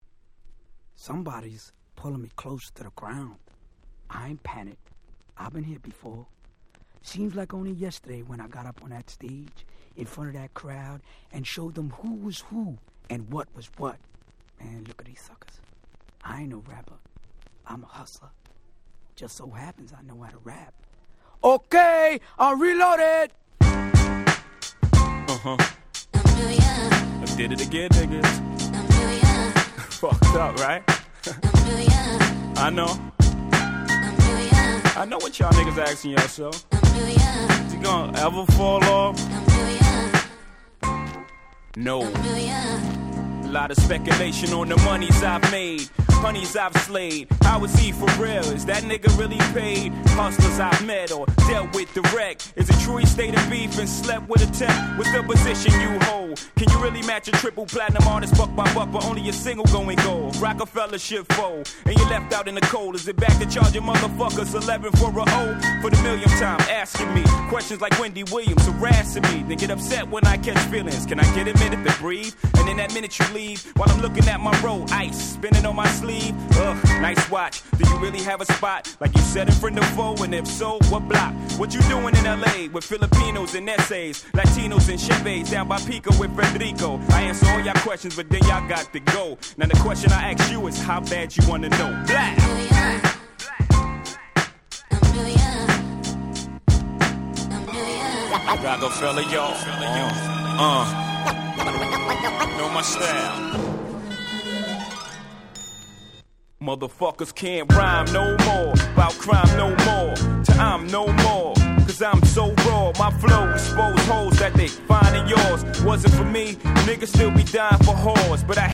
みんな大好き鉄板90's Hip Hop Classics！！